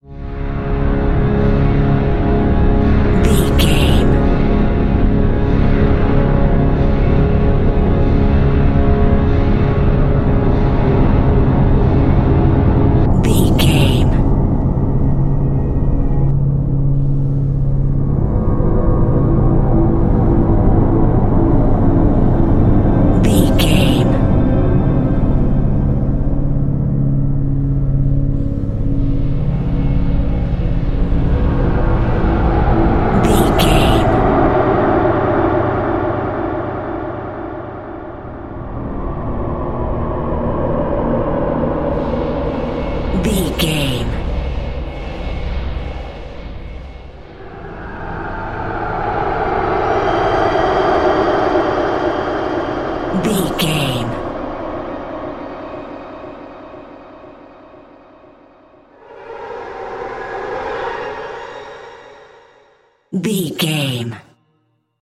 In-crescendo
Aeolian/Minor
C#
Slow
tension
ominous
haunting
eerie
strings
synthesiser
ambience
pads